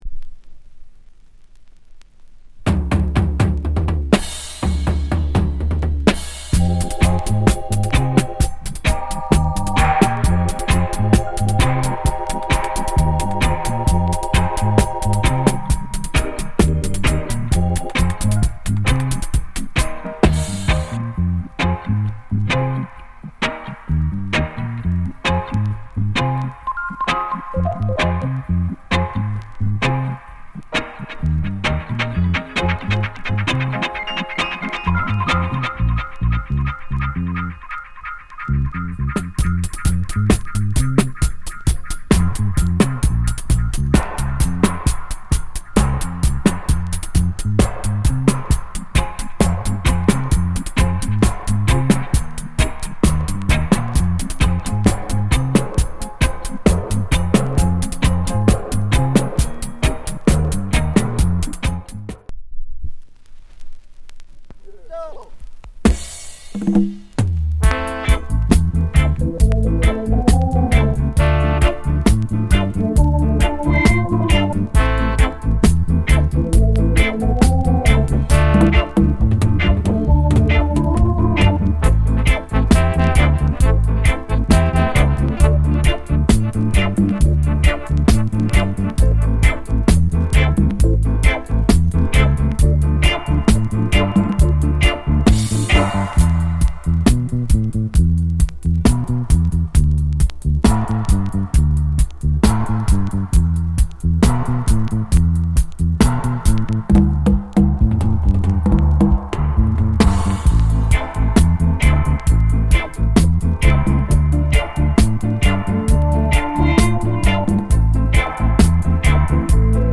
Genre Reggae70sLate / Dubwise
声は無しのピュア・リディム・ダブ・ワイズ。
B-1.5. *** B-1の途中から全面にかけてプレス起因のノイズあります。